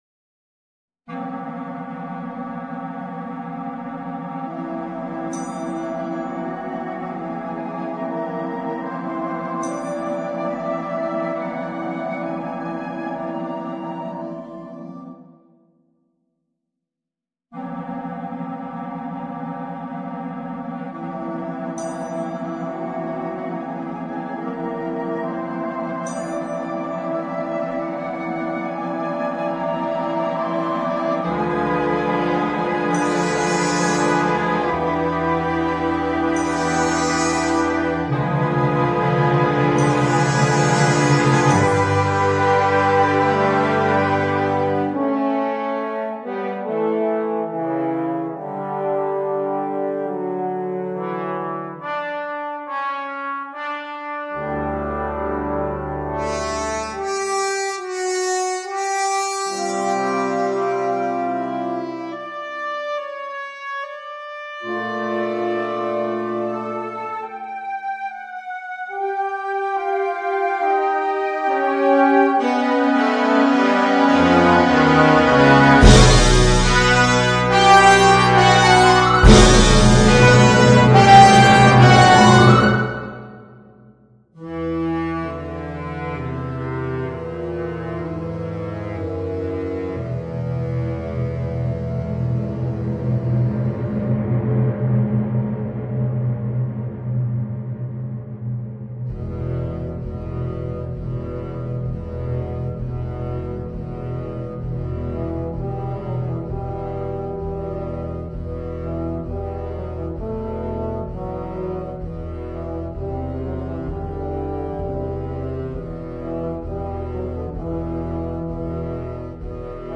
Un affresco musicale di grande impatto.